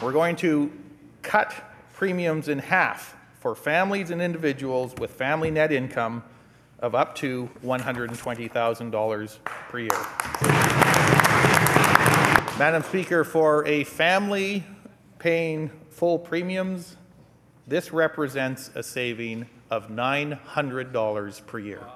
Finance Minister Mike de Jong….